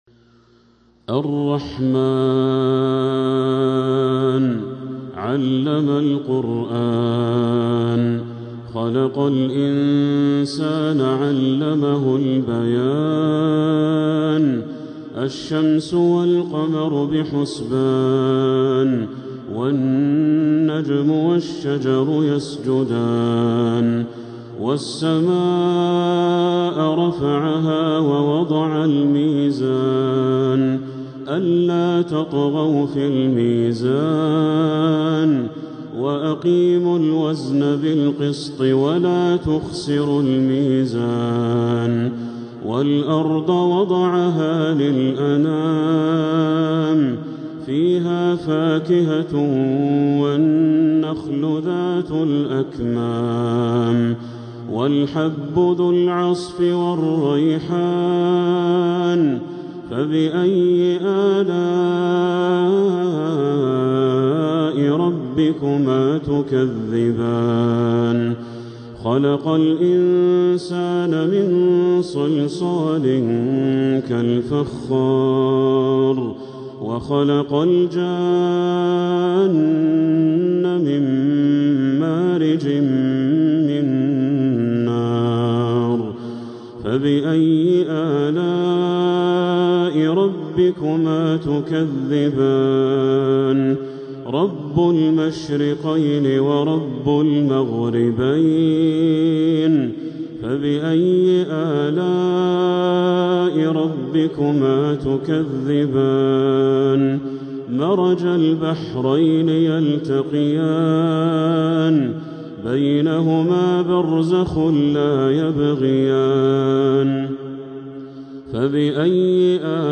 من المسجد الحرام